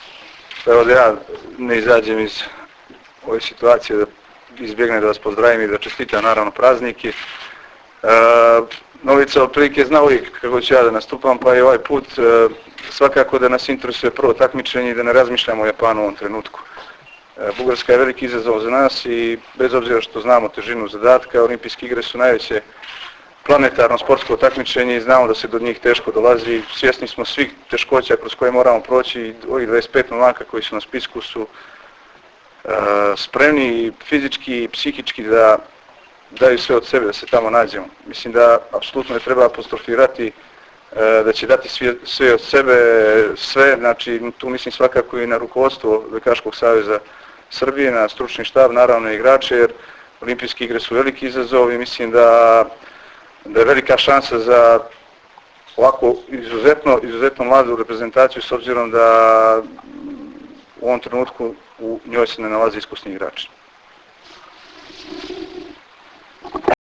IZJAVA IGORA KOLAKOVIĆA 1